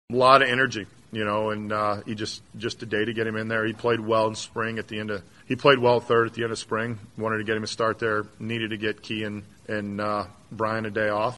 It was another multi-hit game for infielder Michael Chavis, who started at third base while Ke’Bryan Hayes got a day off.  Manager Derek Shelton says Chavis is a valuable tool for the Bucs.